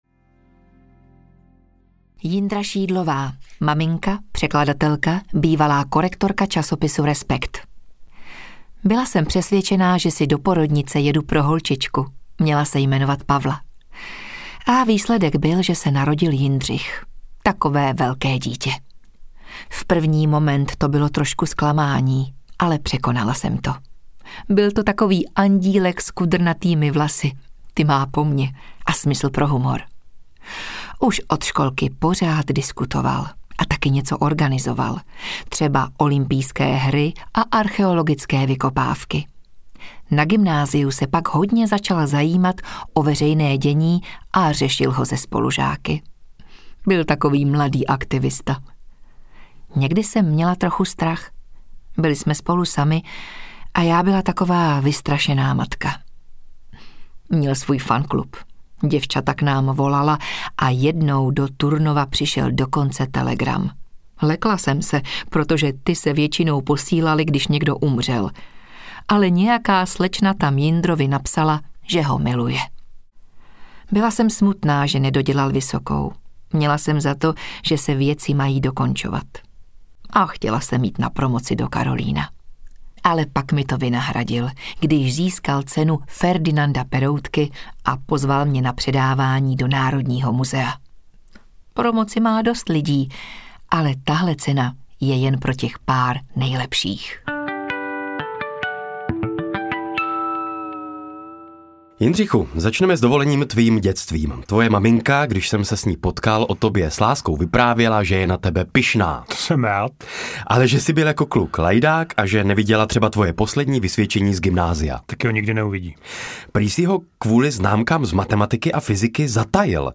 Já si to všechno pamatuju audiokniha
Ukázka z knihy